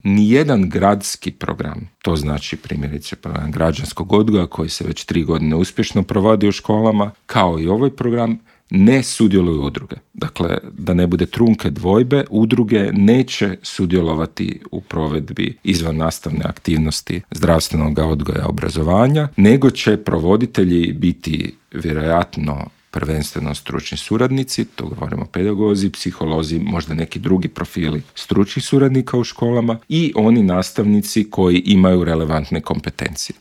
Više od 50 tisuća djece u Hrvatskoj suočava se s mentalnim teškoćama, broj djece s teškoćama u zagrebačkim osnovnim školama više se nego udvostručio u zadnjih deset godina, a više od trećine, odnosno čak 36 posto djece u Hrvatskoj je pretilo, dok je to na razini Europe slučaj s njih 25 posto, iznio je alarmantne podatke Hrvatskog zavoda za javno zdravstvo i UNICEF-a u Intervjuu Media servisa pročelnik Gradskog ureda za obrazovanje Luka Juroš i poručio: